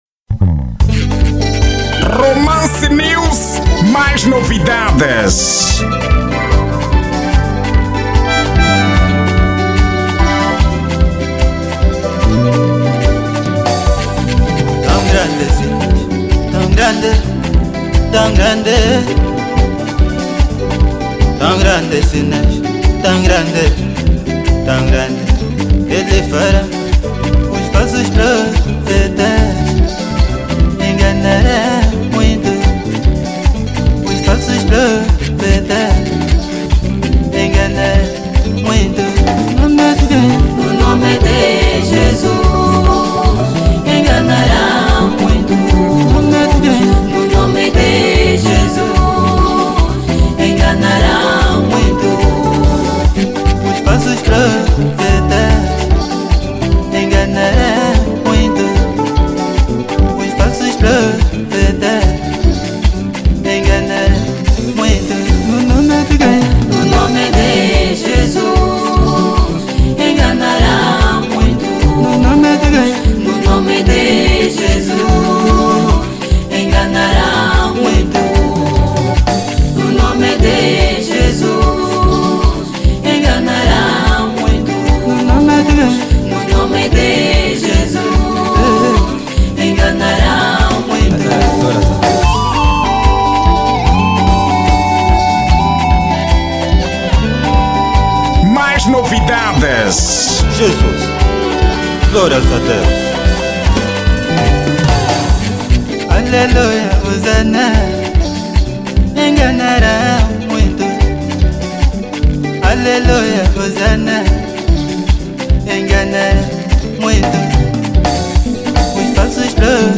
Estilo: Louvor